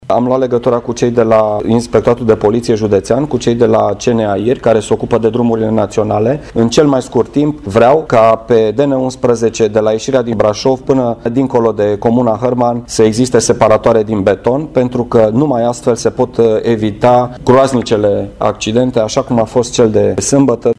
După cum a precizat prefectul Brașovului, se are în vedere delimitarea sensurilor de circulație prin montarea unor separatoare din beton: